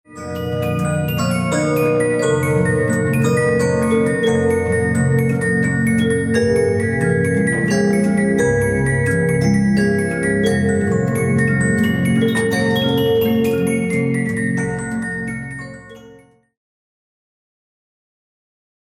Boîte à musique à disque métallique.
La surface du disque est crantée actionnant une roue étoilée qui va relever les lamelles du clavier musical.